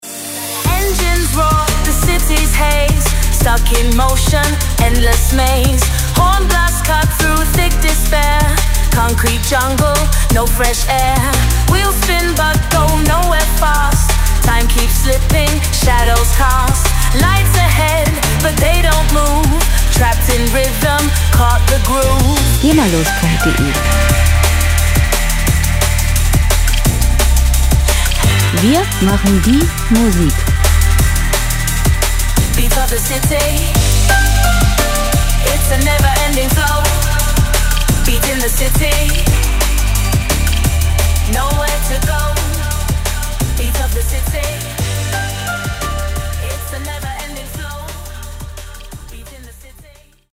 Hintergrundmusik - Großstadt Impressionen
Musikstil: Liquid DnB
Tempo: 117 bpm
Tonart: G-Moll
Charakter: fließend, pulsierend
Instrumentierung: DnB Sängerin, Synthesizer, Piano